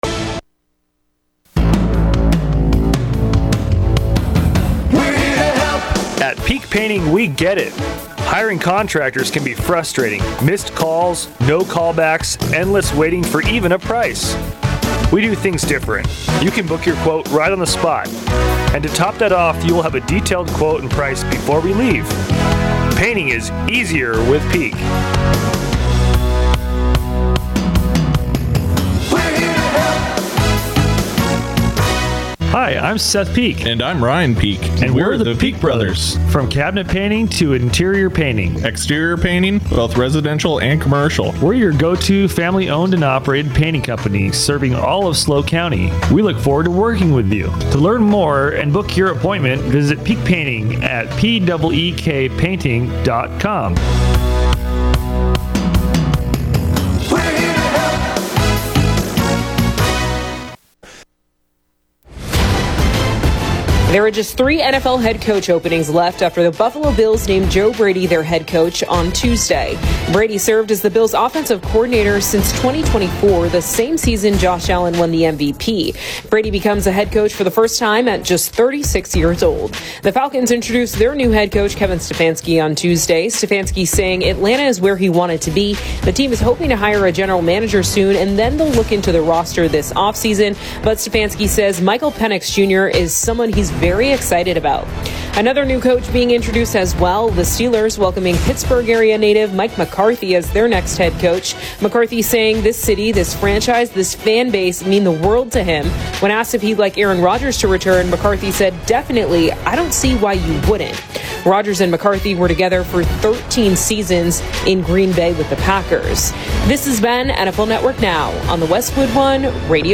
The Morning Exchange; North County’s local news show airs 6 a.m. to 9 a.m. every weekday.